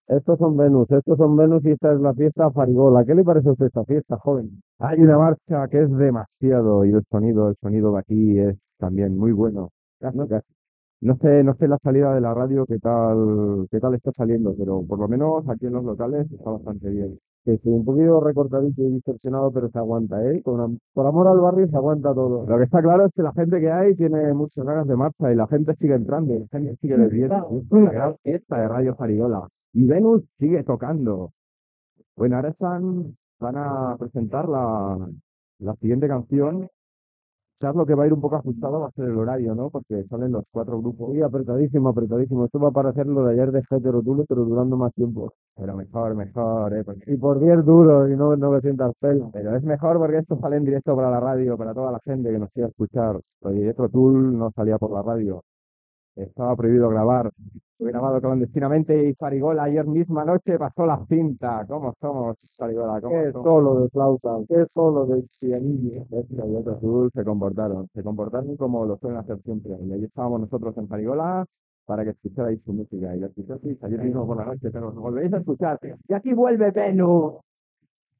Transmissió de la Festa de Ràdio Farigola a Nou Barris on actúa el grup Venus, amb esment al concert fet a Barcelona per Jethro Tull.